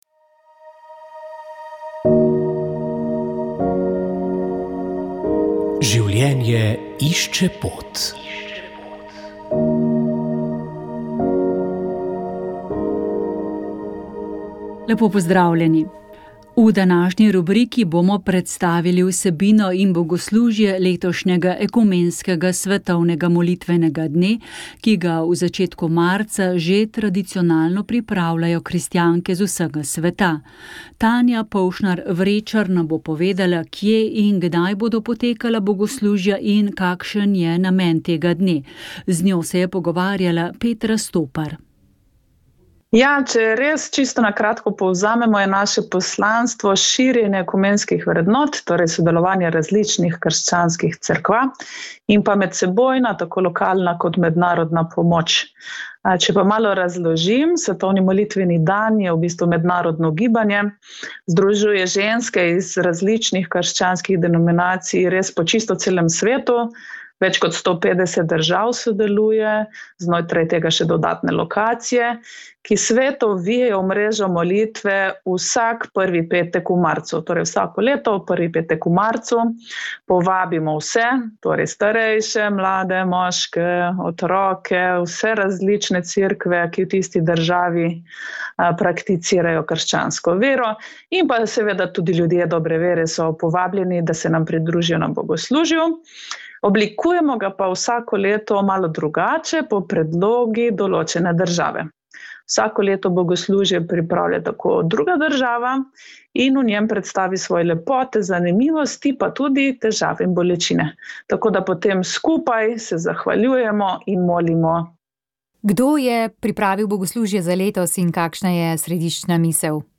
Na Univerzi Sigmunda Freuda so pripravili aktualno okroglo mizo: Žalovanje v času paliativne oskrbe in umiranja bližnje osebe.